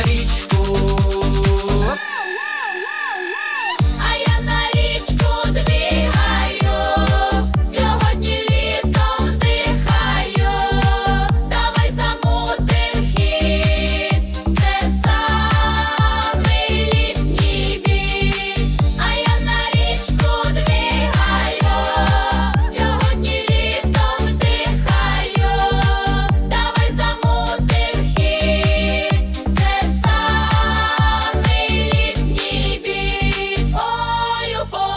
Pop
Жанр: Поп музыка / Украинские